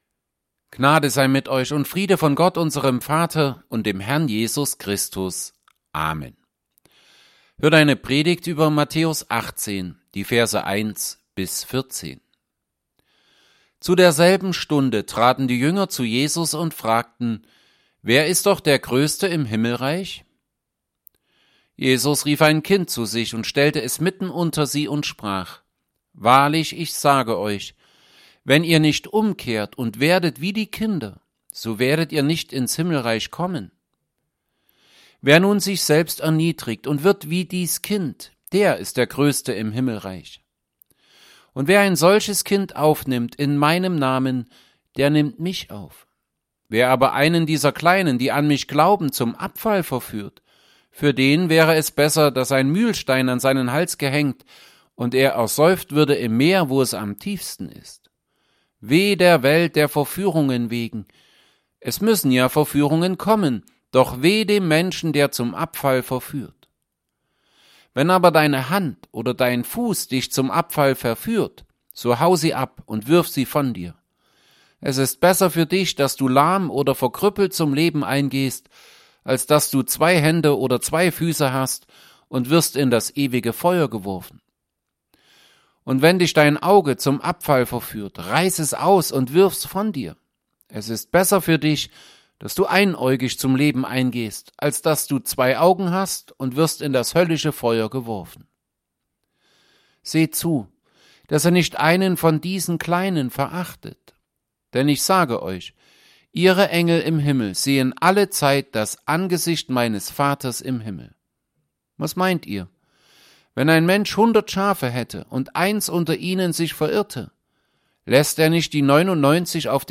Gottesdienst
Predigt_zu_Matthäus_18_1b14.mp3